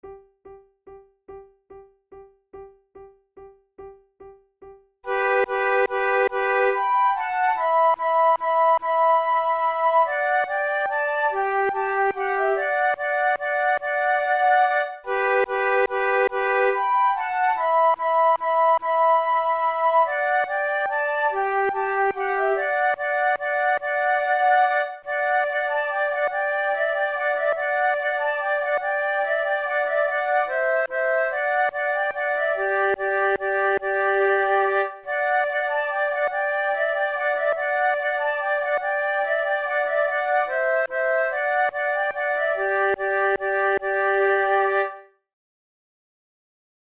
KeyG major
Time signature3/4
Tempo144 BPM